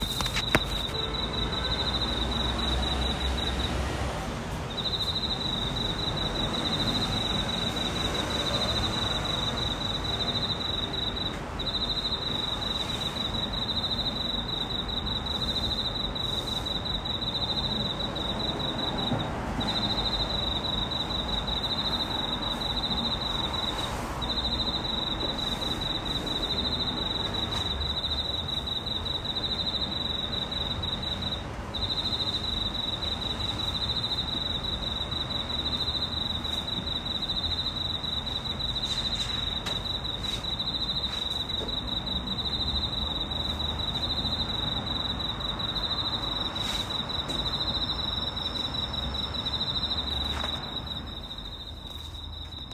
コオロギの鳴き声 | 株式会社キレイカ・コピーのブログ